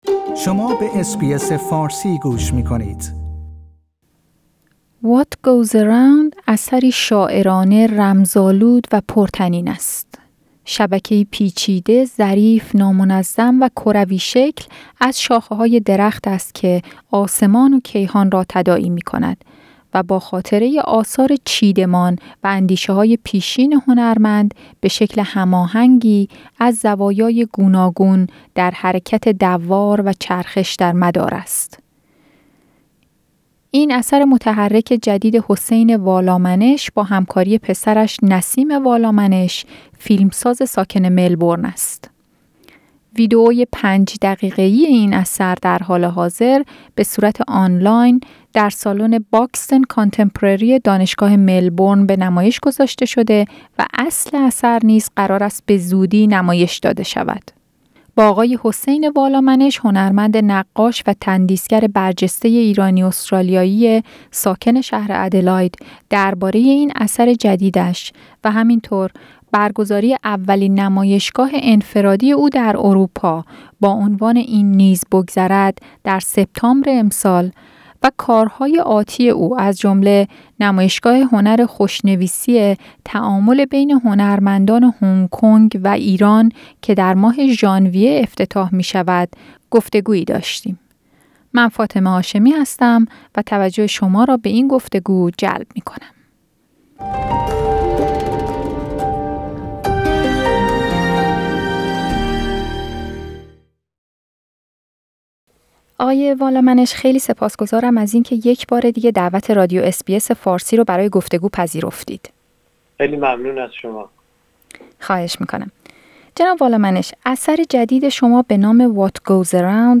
با حسین والامنش هنرمند نقاش و تندیس‌گر برجسته ایرانی-استرالیایی ساکن شهر آدلاید درباره این اثر جدیدش و همینطور، برگزاری اولین نمایشگاه انفرادی او در اروپا با عنوان «این نیز بگذرد» در سپتامبر امسال ، و کارهای آتی او از جمله نمایشگاه هنر خوشنویسی: تعامل بین هنرمندان هنگ کنگ و ایران که در ماه ژانویه افتتاح می‌شود گفتگویی داشتیم که توجه شما را به آن جلب می‌کنیم.